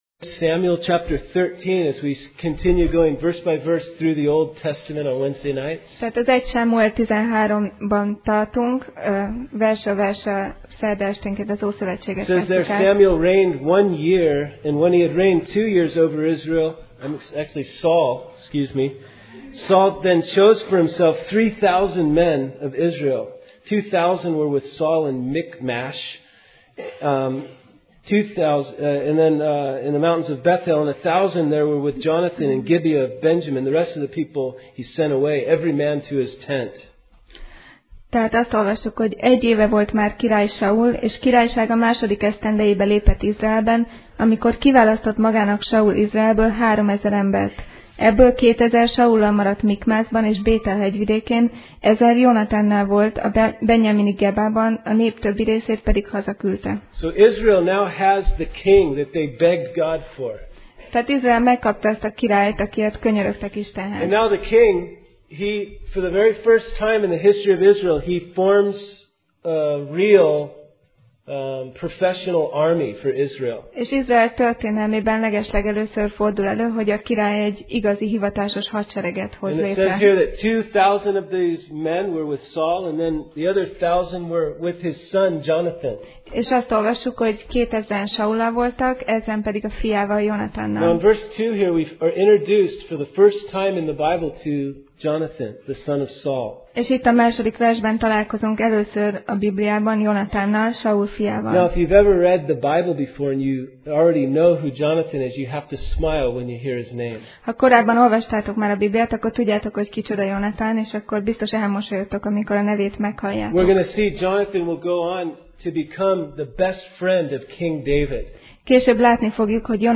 1Sámuel Passage: 1Sámuel (1Samuel) 13:1-4 Alkalom: Szerda Este